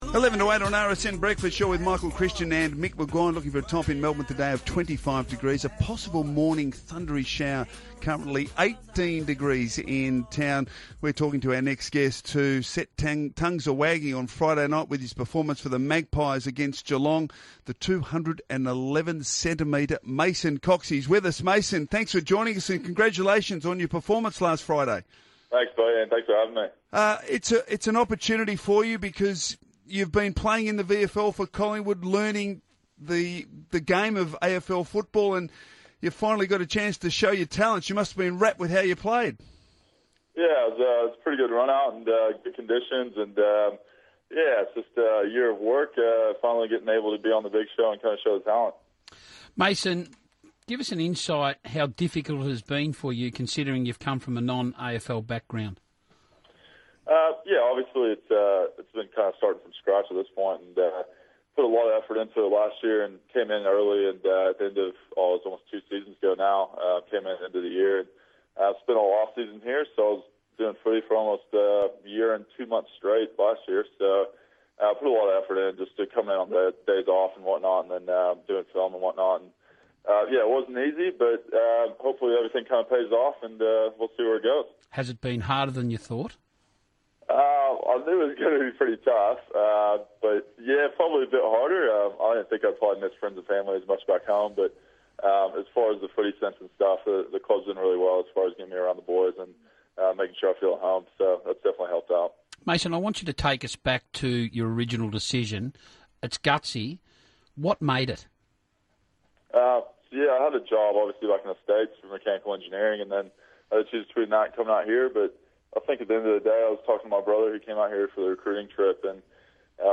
Radio: Mason Cox on RSN Breakfast
Listen as Mason Cox joins Michael Christian and Mick McGuane on RSN's Breakfast Show.